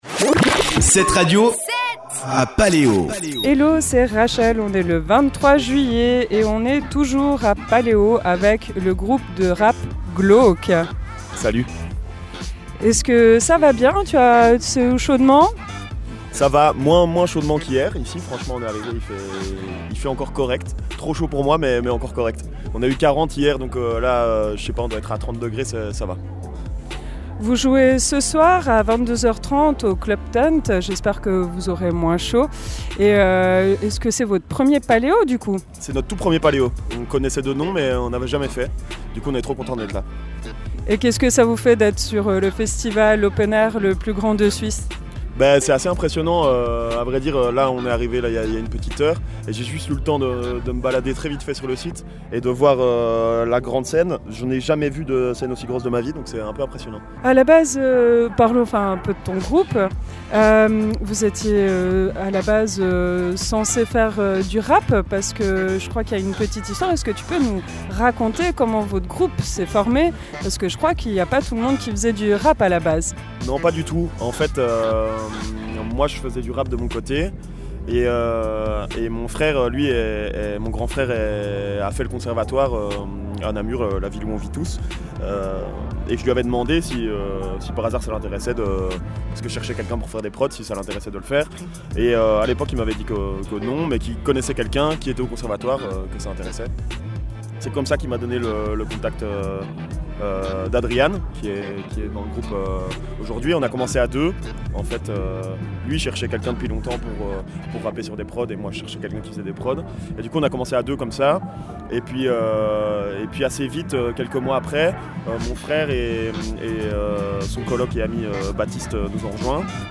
Paléo 2022 – Interview Glauque
Paleo22-Glauque-Itw.mp3